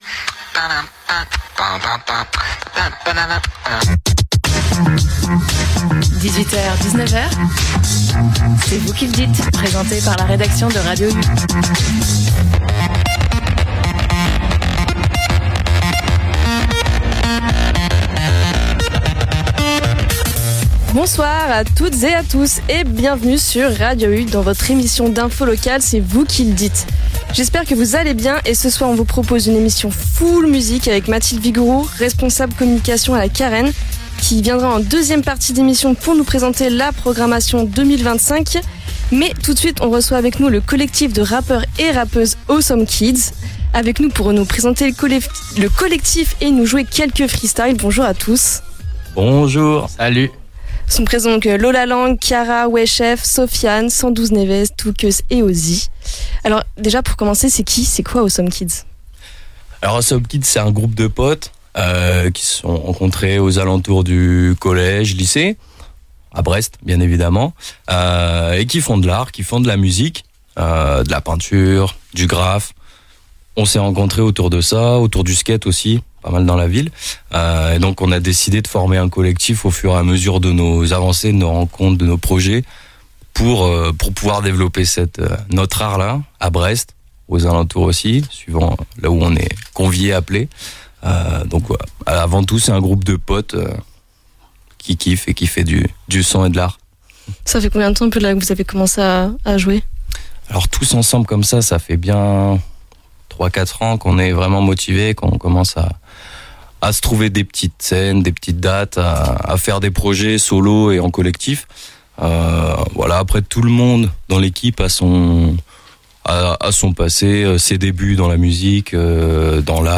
Dans cette émission : – Une performance fleuve et inédite du collectif de rappeurs.ses "Awesome Kids" – La programmation de la nouvelle saison de la Carène.